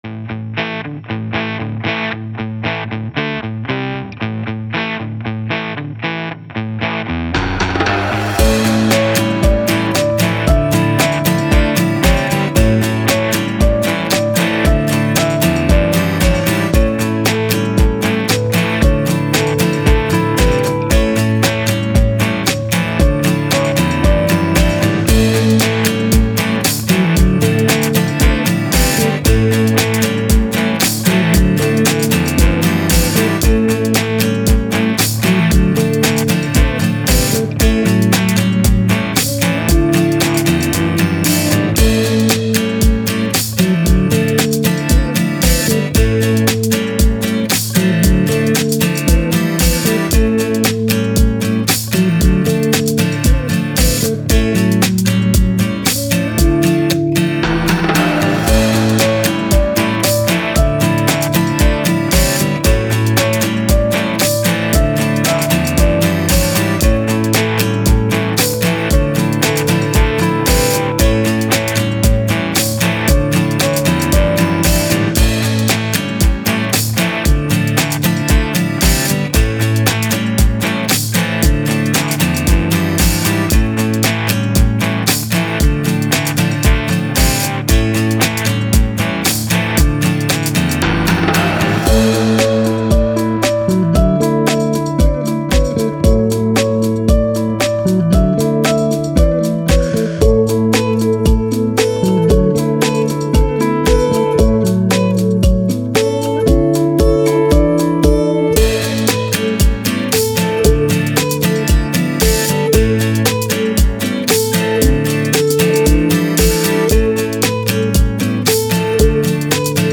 Pop, Playful, Upbeat, Positive